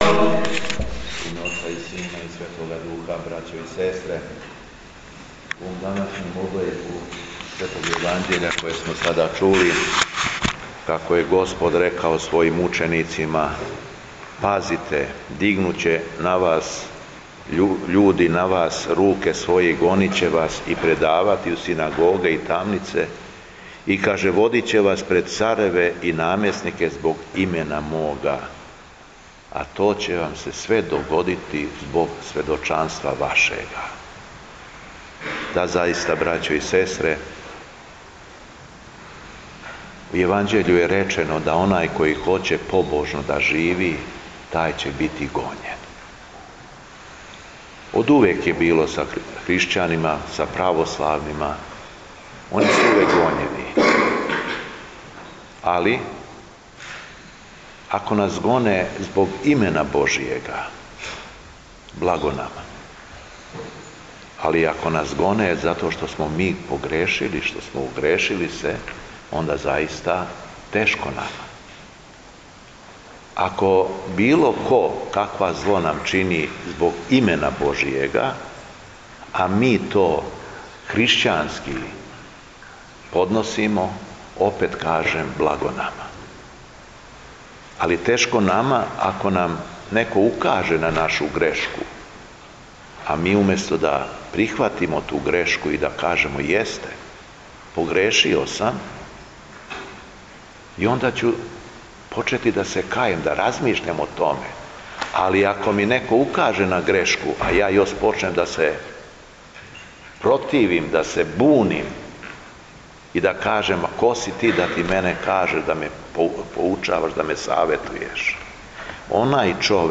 ЛИТУРГИЈСКО САБРАЊЕ У СТАРОЈ ЦРКВИ У КРАГУЈЕВЦУ - Епархија Шумадијска
Беседа Његовог Високопреосвештенства Митрополита шумадијског г. Јована